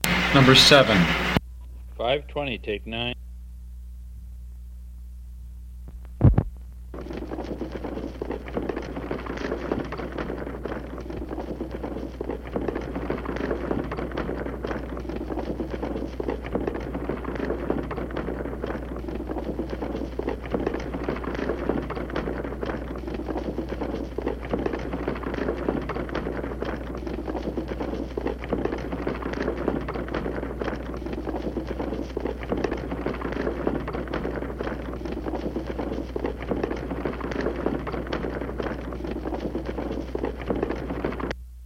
古老的马车 " G5207马车
描述：马车，稳定的轮子转动和马在木头或坚硬的表面上。微妙的吱吱声。
我已将它们数字化以便保存，但它们尚未恢复并且有一些噪音。